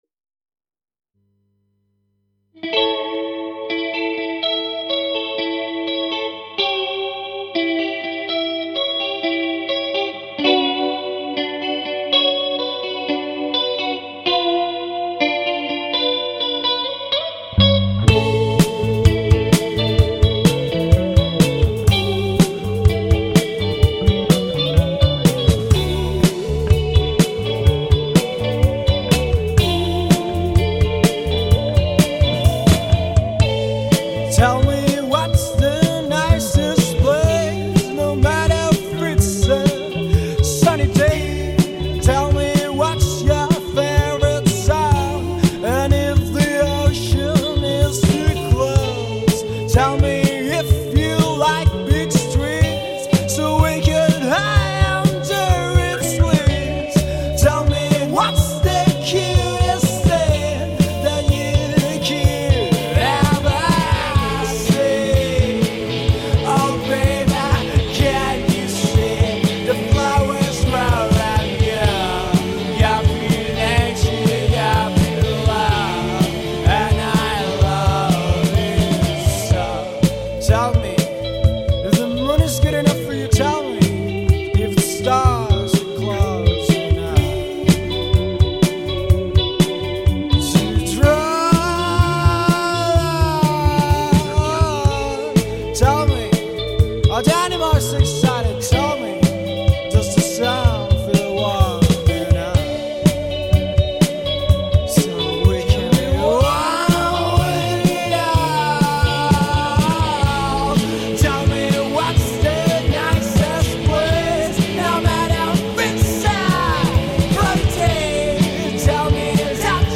Espacio musical variopinta destacando el rock. Volando de aquí para allá dando cabida en novedades, agenda, anécdotas e incluyendo intereses audiovisuales.